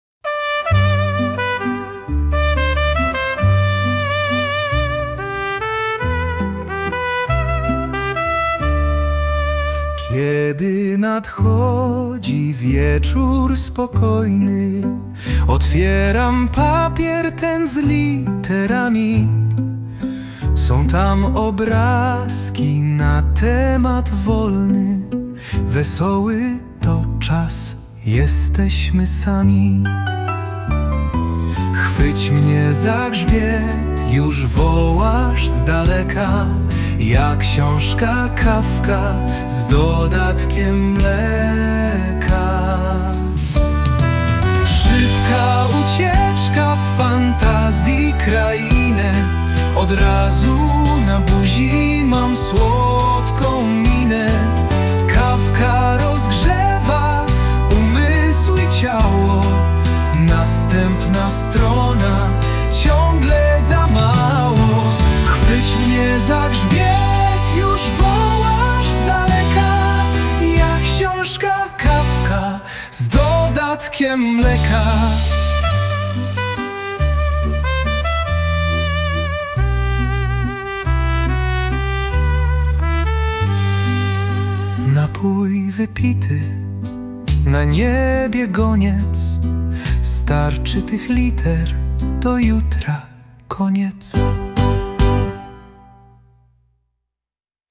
Do zdjęć załączamy piosenkę-niespodziankę
wykonanie: AI.